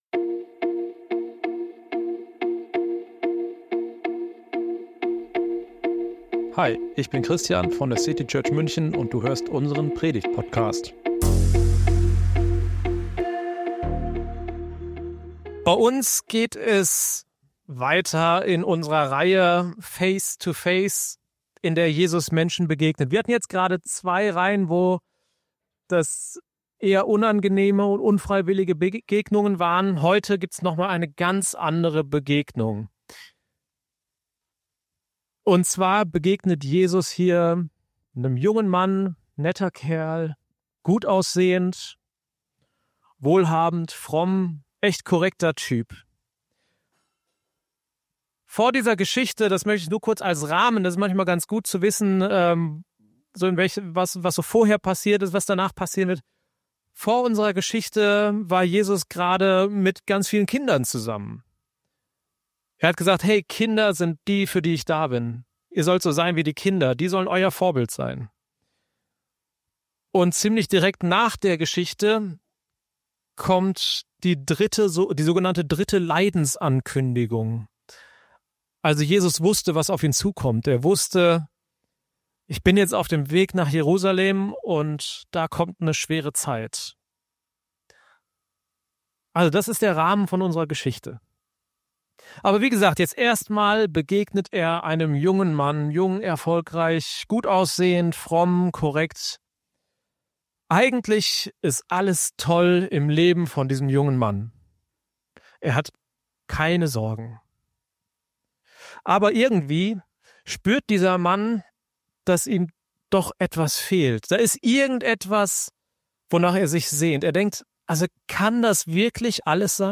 Eine dieser Begegnungen schauen wir uns in dieser Predigt an.